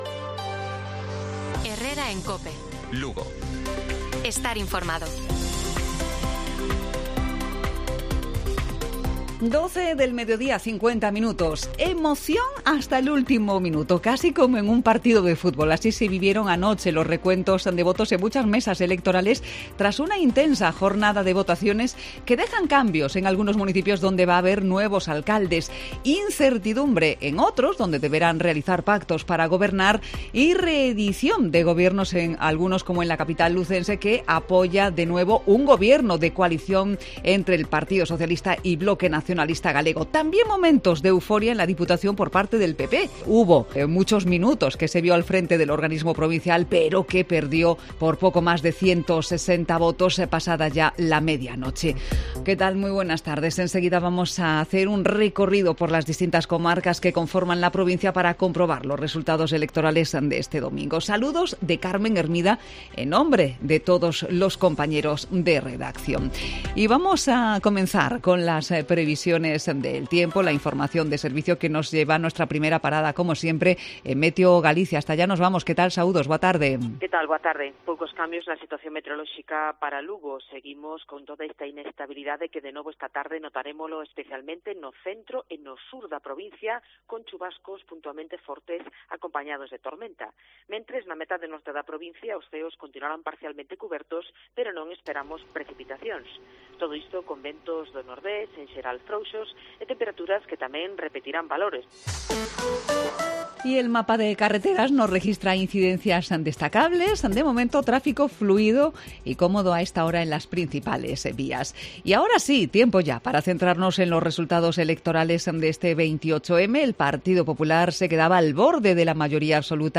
Informativo Provincial de Cope Lugo. 29 de mayo. 12:50 horas